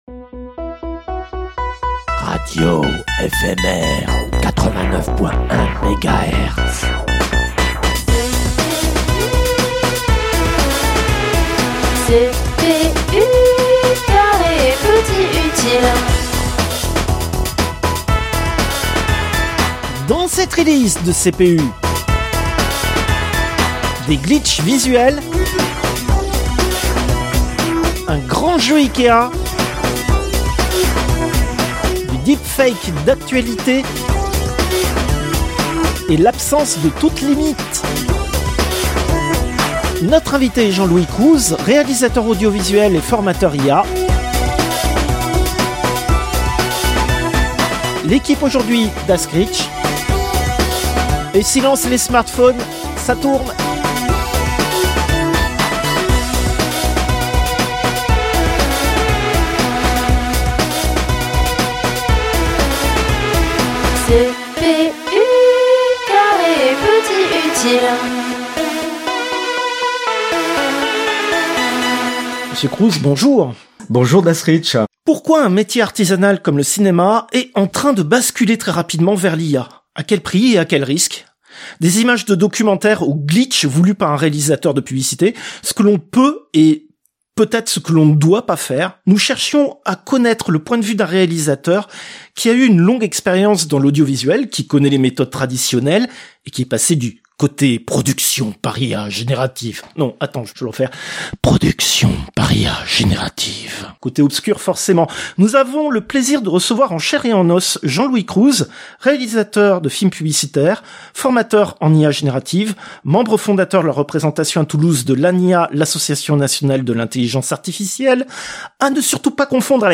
Interview, troisième partie